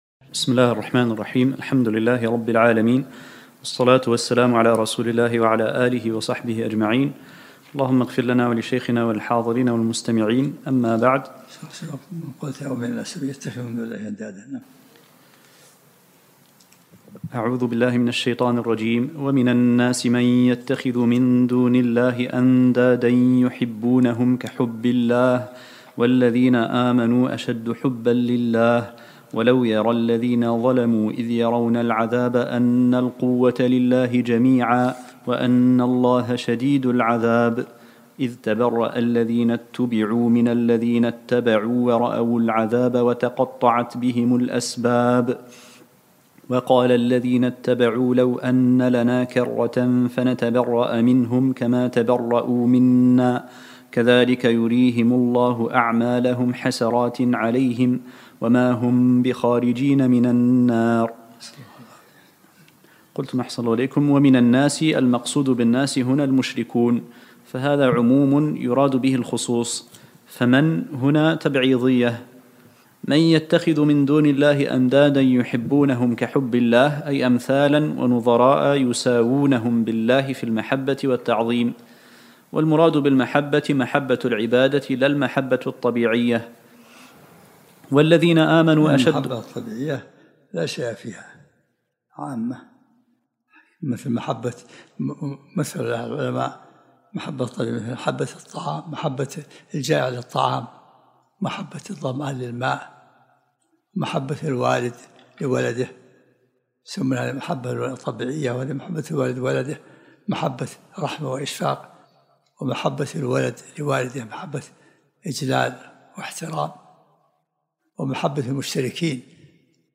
الدرس الثاني عشرمن سورة البقرة